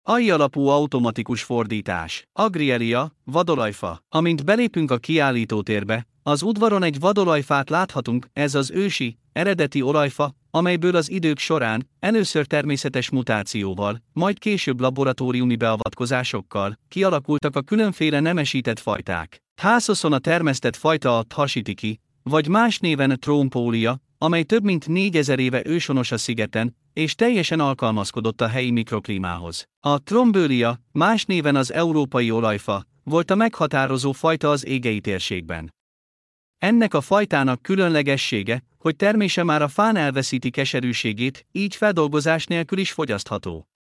Hangalapú idegenvezetés